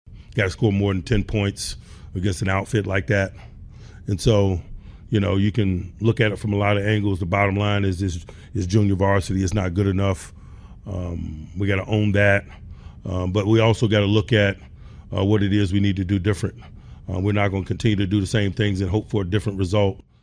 An angry coach Mike Tomlin says the Steelers just weren’t good enough.